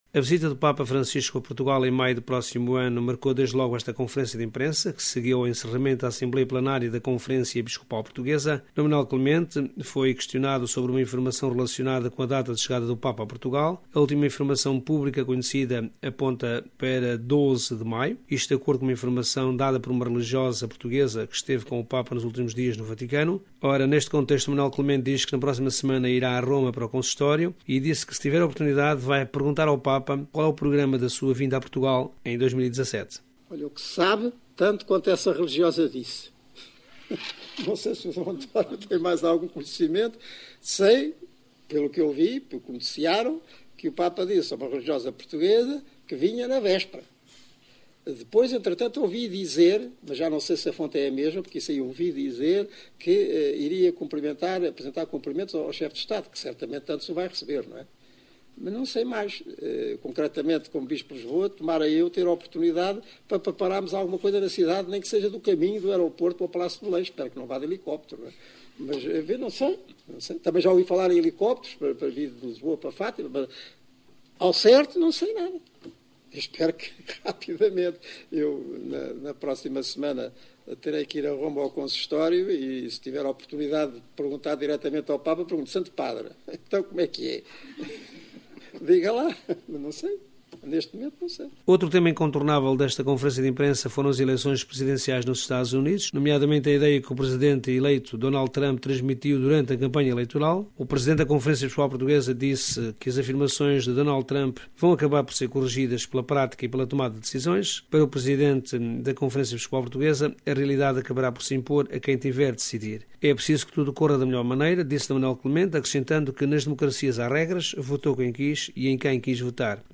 De Lisboa, o nosso correspondente